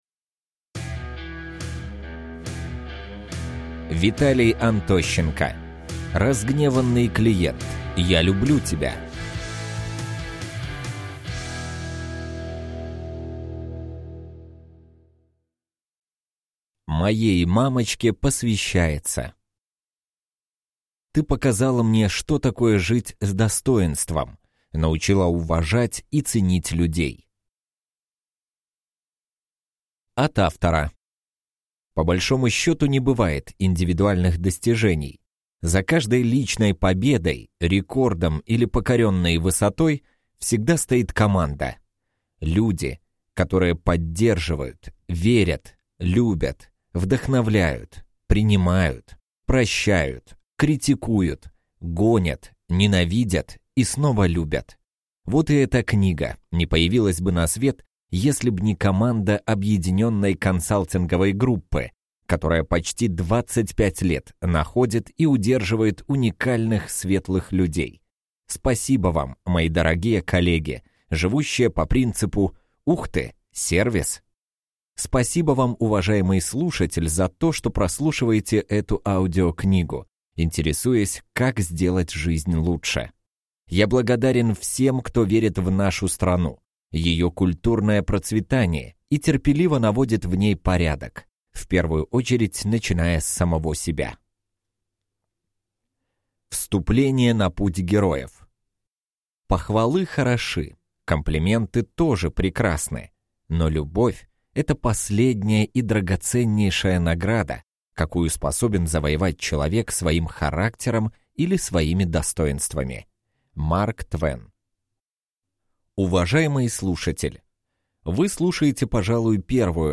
Аудиокнига Разгневанный Клиент, я люблю тебя | Библиотека аудиокниг